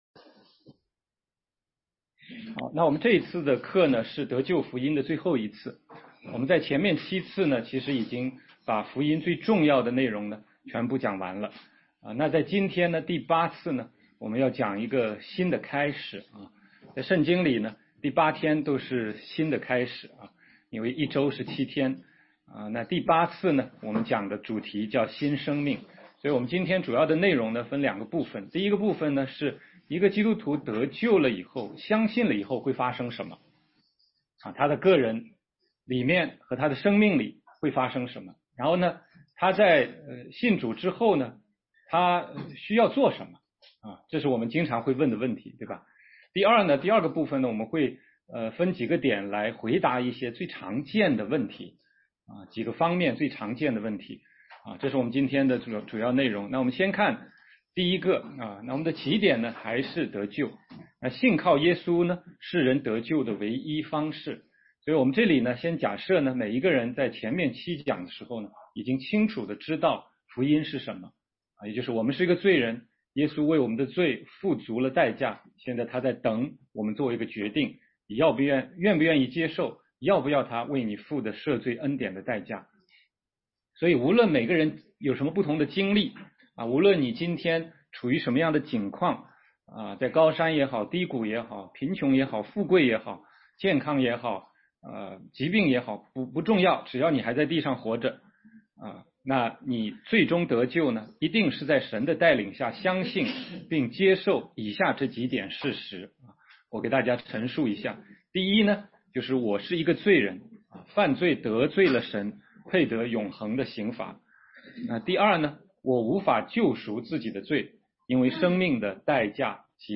16街讲道录音 - 得救的福音第八讲：新生命